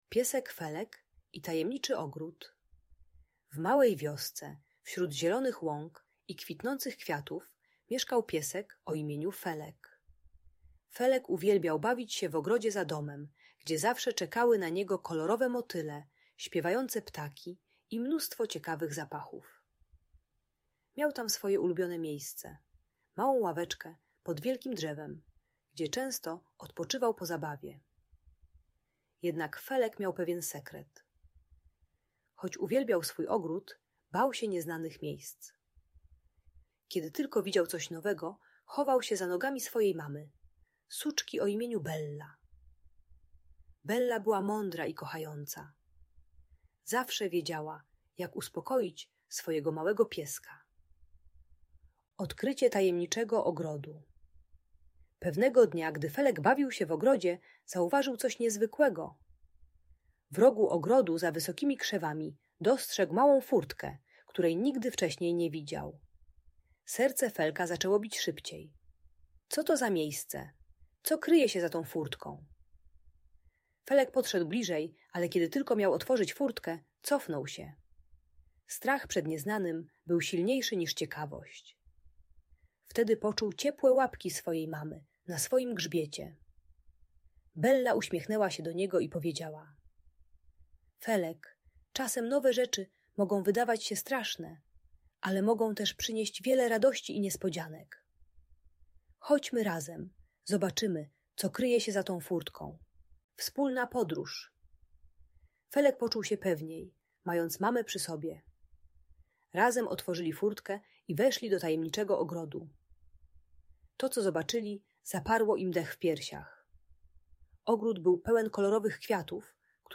Piesek Felek i Tajemniczy Ogród - Lęk wycofanie | Audiobajka